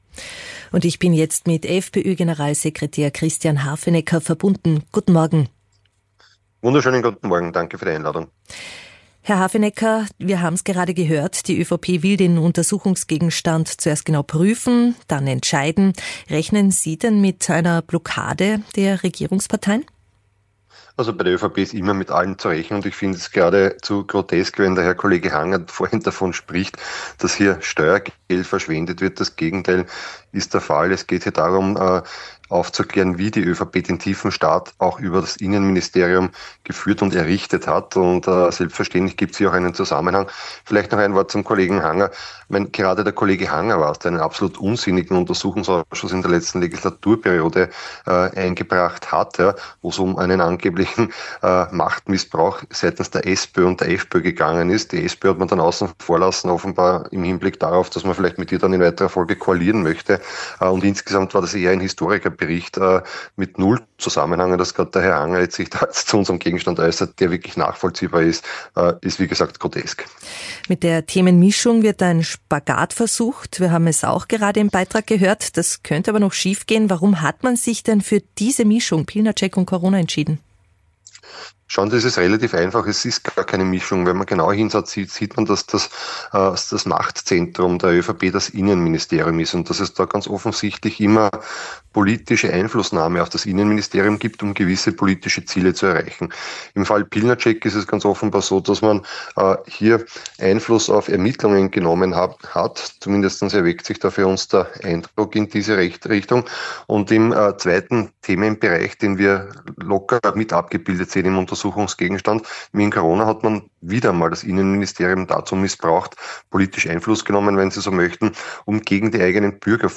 Gestern wurde im Morgenjournal von Ö1 FPÖ-Generalsekretär Christian Hafenecker ungehalten.
Der Begriff werde häufig in rechtsextremen Kreisen und Verschwörungstheorien verwendet: ob ihm, Hafenecker, das bewusst sei. Darauf wurde Hafenecker hörbar zornig; er lasse sich nicht vom ORF als Rechtsextremisten darstellen – das hat in dem Gespräch auch niemand getan – und versuchte dann, das Innenministerium als eine Form eines „Staats im Staat“ darzustellen – was an einer genauen Definition von „Deep State“ scheitern musste.